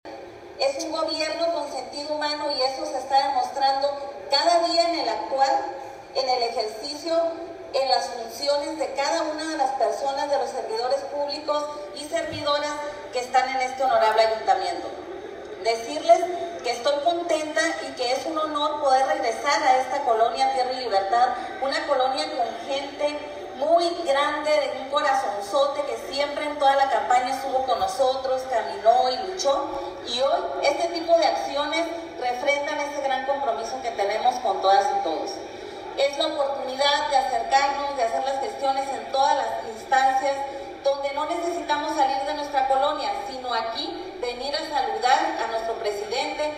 En la jornada de audiencias públicas celebradas este miércoles en coordinación con el Ayuntamiento de Los Cabos, en el Parque de la Colonia Tierra y Libertad del Distrito IX que ella representa, la legisladora enfatizó que el Congreso y los gobiernos de la 4ª transformación, trabajan para recuperar la confianza de la ciudadanía, profundizar la democracia y mejorar la gestión de las instituciones públicas del estado y país.